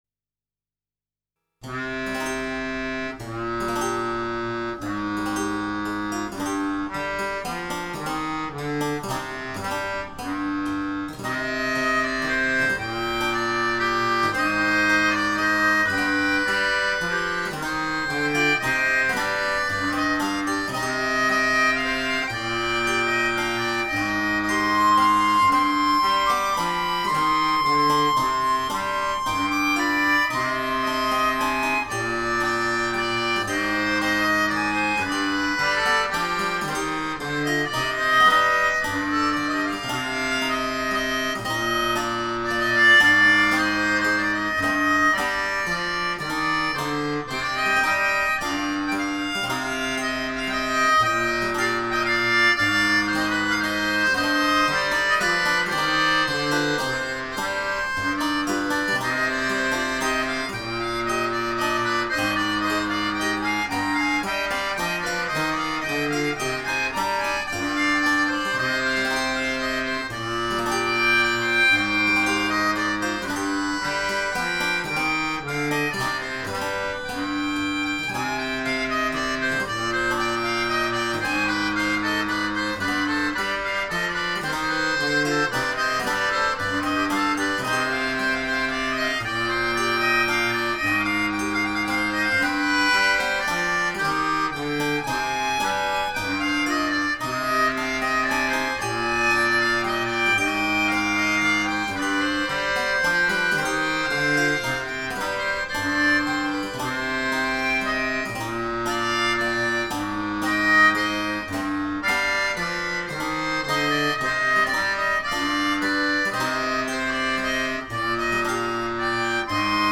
Melodion
Harmonica
and Qchord